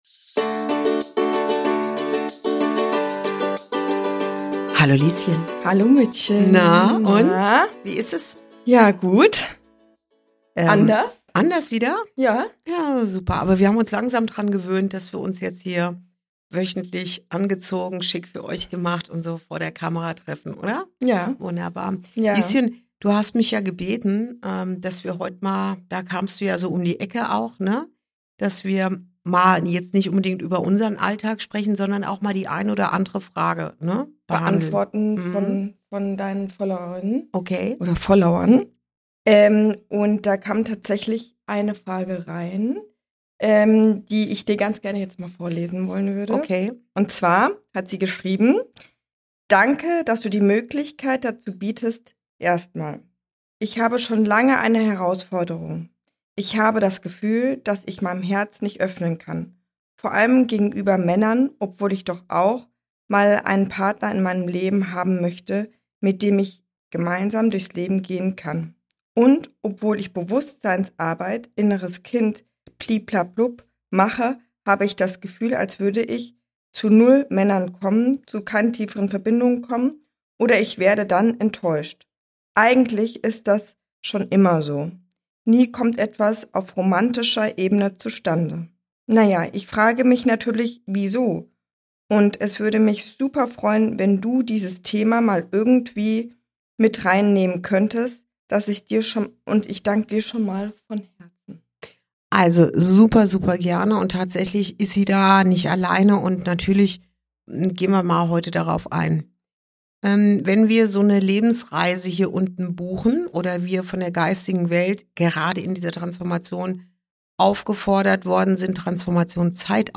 Folge 29: Warum deine größte Sehnsucht (noch) unerfüllt ist & was du dabei lernen darfst ~ Inside Out - Ein Gespräch zwischen Mutter und Tochter Podcast